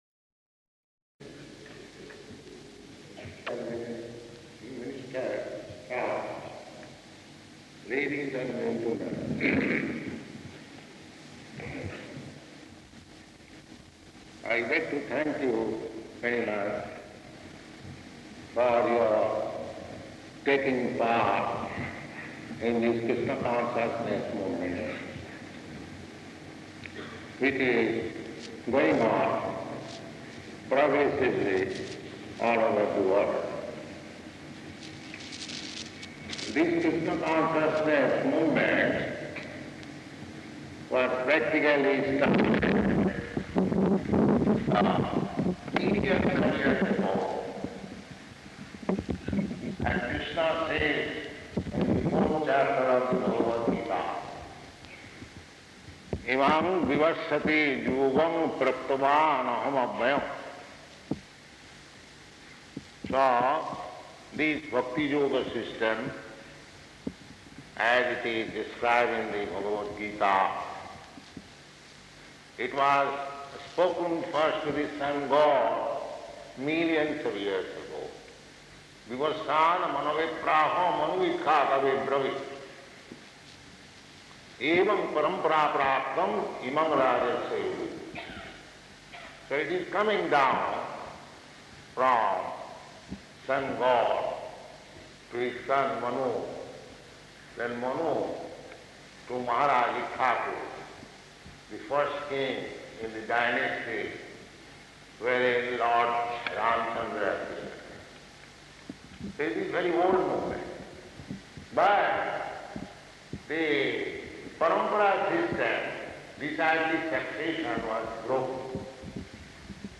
Lecture at Town Hall
Type: Lectures and Addresses
Location: Kuala Lumpur